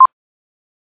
BEEPHIG0.mp3